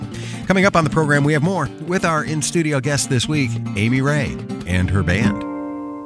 (webstream capture)